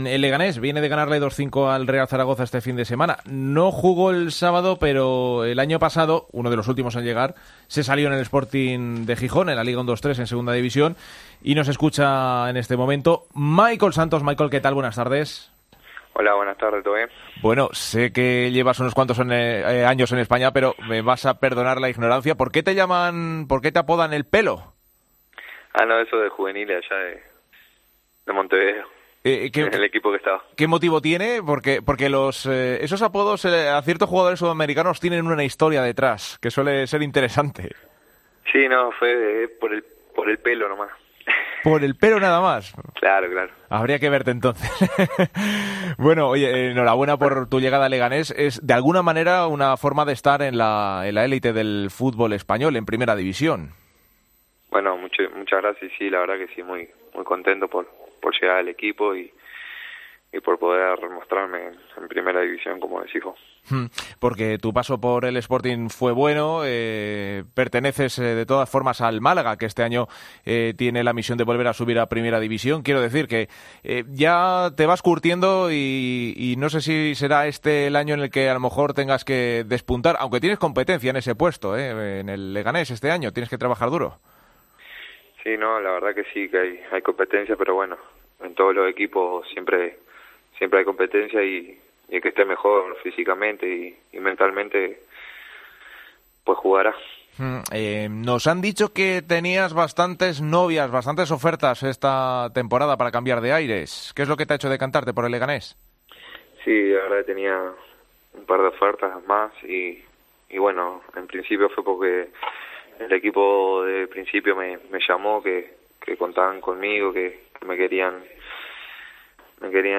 Hablamos con el nuevo fichaje del Leganés: "Muy contento por llegar al equipo y jugar en Primera División. En todos los equipos hay competencia"